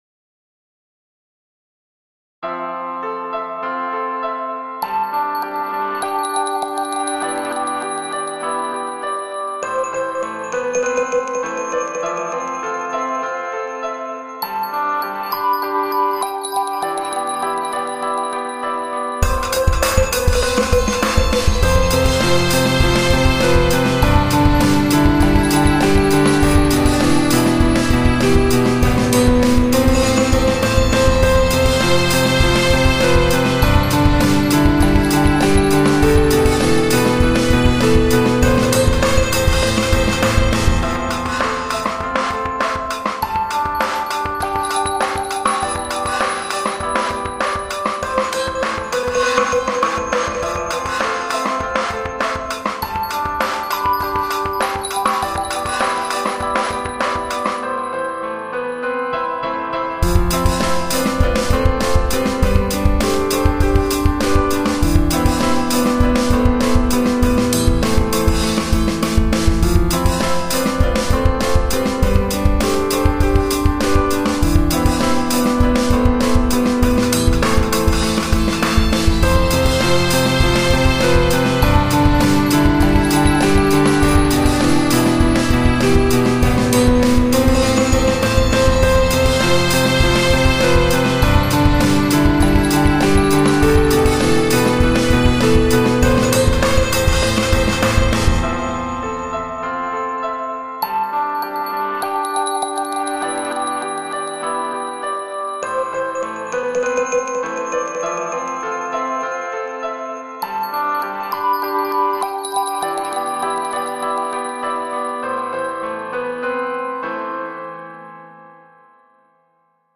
专辑类别：EP、单曲